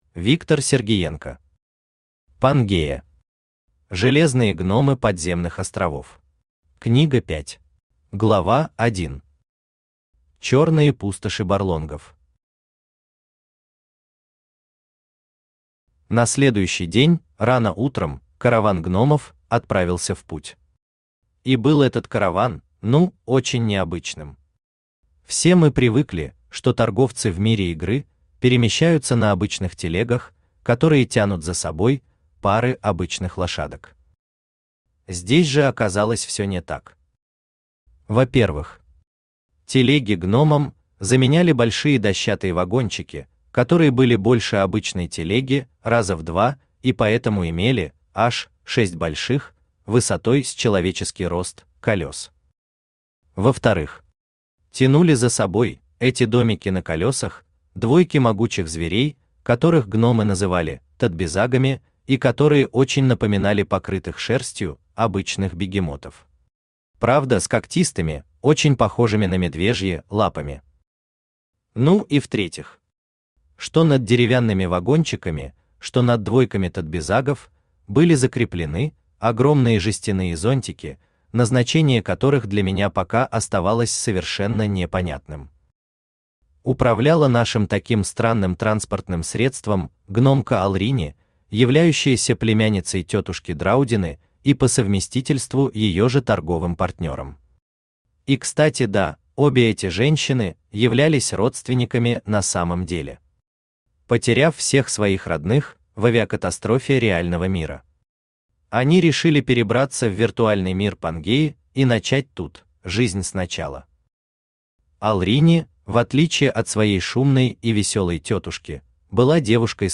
Аудиокнига Пангея. Железные гномы подземных островов. Книга 5 | Библиотека аудиокниг
Aудиокнига Пангея. Железные гномы подземных островов. Книга 5 Автор Виктор Николаевич Сергиенко Читает аудиокнигу Авточтец ЛитРес.